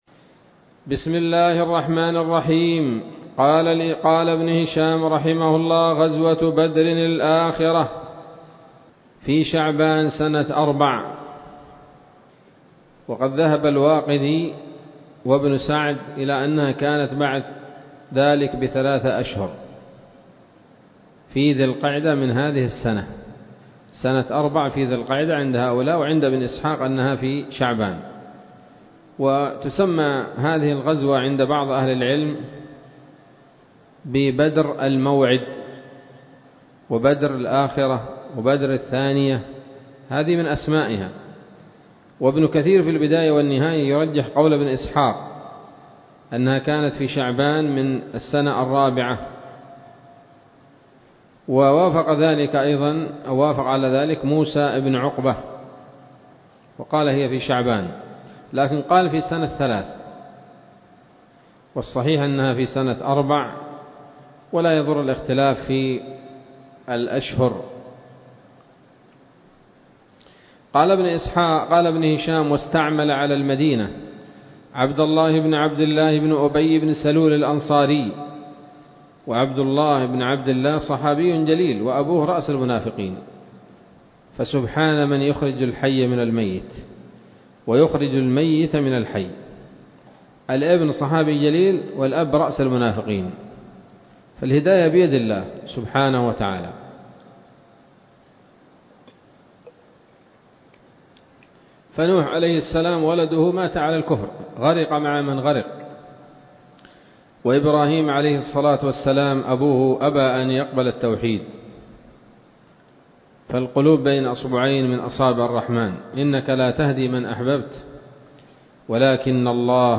الدرس السادس والتسعون بعد المائة من التعليق على كتاب السيرة النبوية لابن هشام